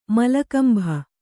♪ malla kambha